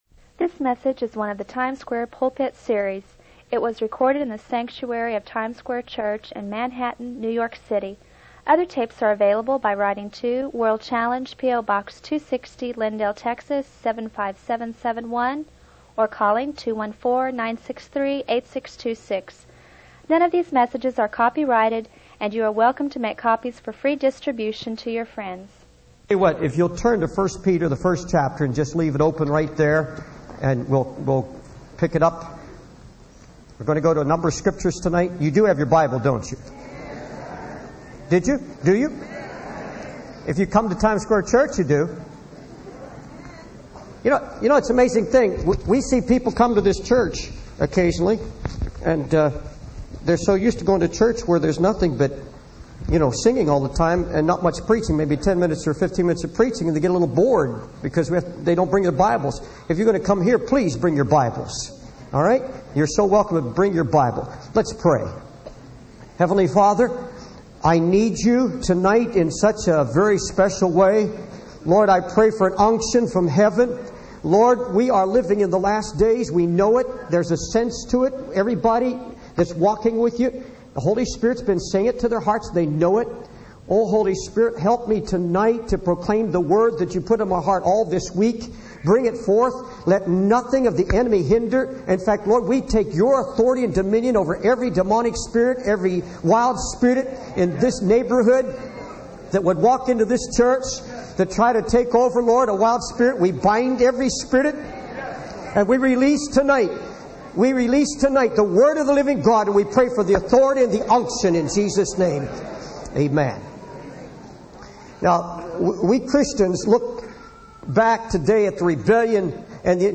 In this sermon, the preacher emphasizes the importance of coming out of a certain entity, referred to as 'her.' He mentions the historical context of the United States' involvement in wars, highlighting the losses in Korea and Vietnam.
It was recorded in the sanctuary of Times Square Church in Manhattan, New York City.